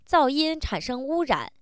neutral